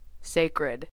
Ääntäminen
IPA : /ˈseɪkrɪd/